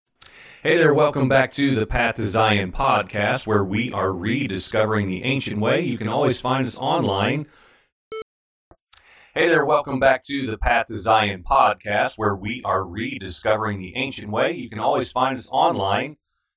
New user-Unable to reduce echo
Something with the headphones created an echo that I can’t figure out how to clean up.
Nothing seems to take out or even greatly reduce the echo in the audio… to my ears.